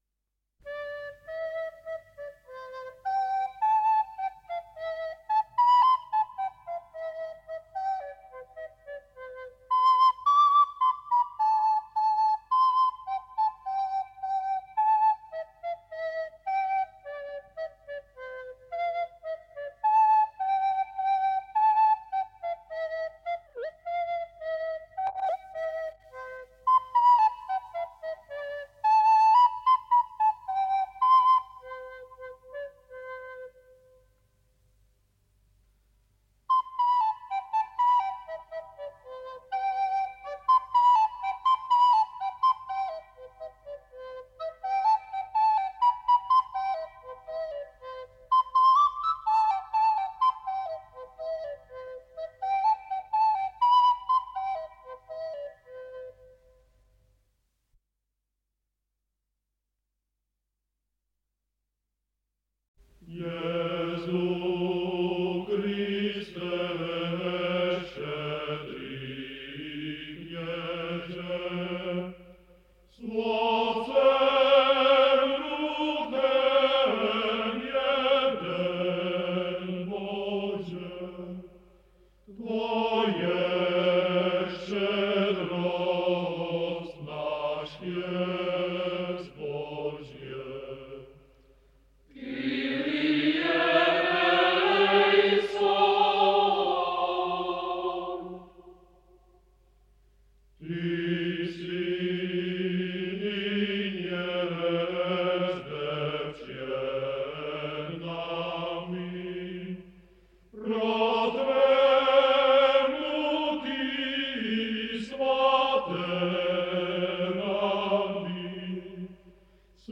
AudioKniha ke stažení, 14 x mp3, délka 32 min., velikost 28,5 MB, česky